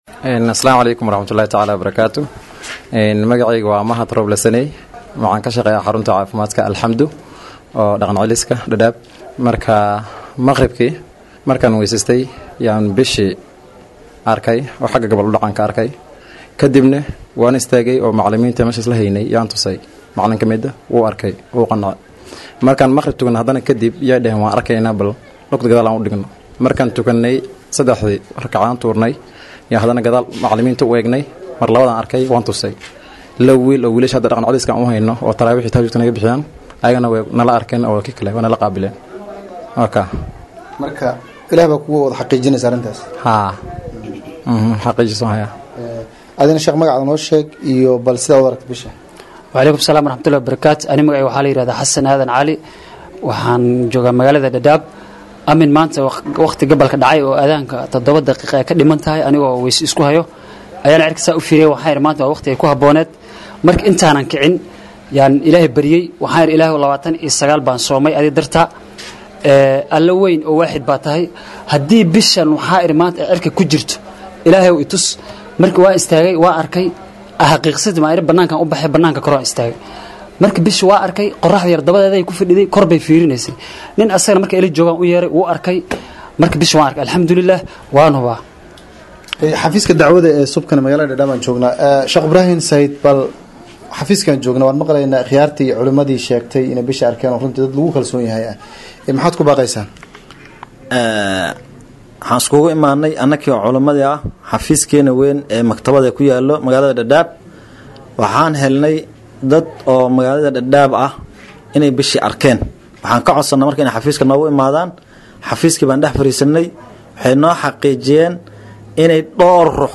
Meelaha bisha laga arkay waxaa ka mid ah deegaanka Dadaab iyadoo dadkii ay indhahooda qabteen iyo culimada ay u warrameen warbaahinta star.
Dadka-bisha-arkay-ee-Dadaab.mp3